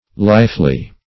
lifely - definition of lifely - synonyms, pronunciation, spelling from Free Dictionary Search Result for " lifely" : The Collaborative International Dictionary of English v.0.48: Lifely \Life"ly\, adv.